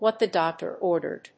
アクセント(júst) whàt the dóctor órdered